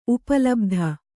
♪ upa labdha